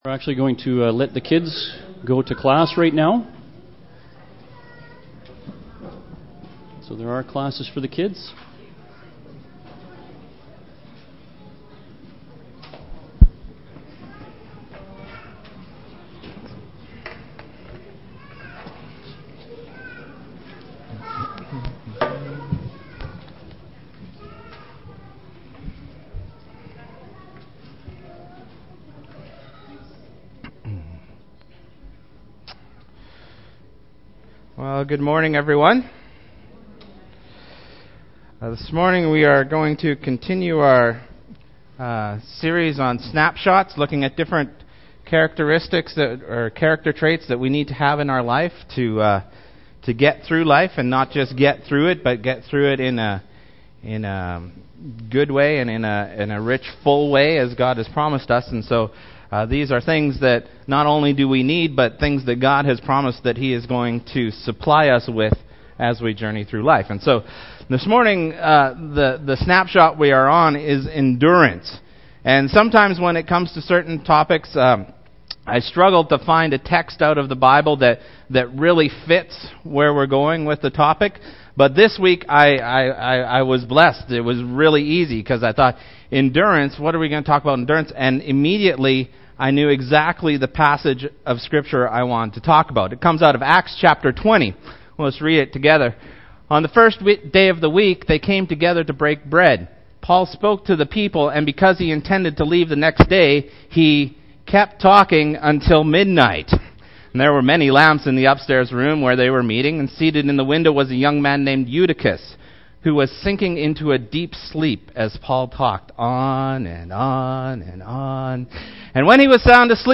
Sermon Series | Church of Christ Saskatoon